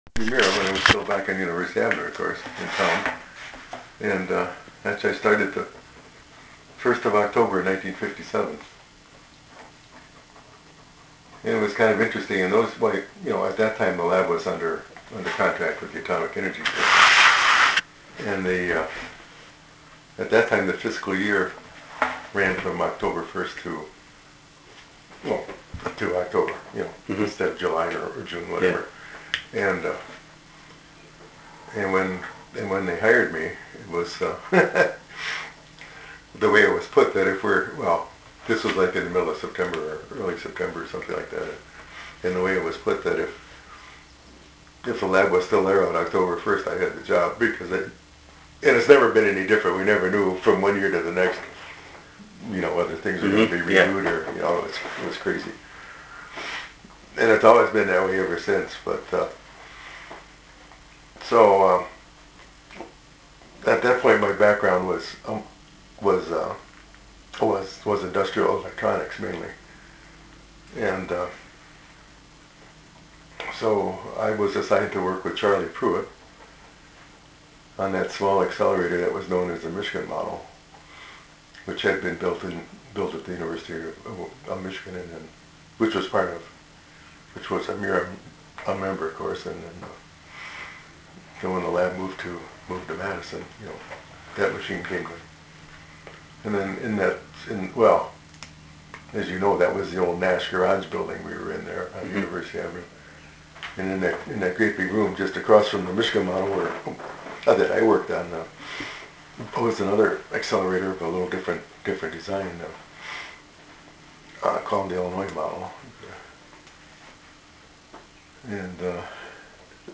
Part 1 of an oral history interview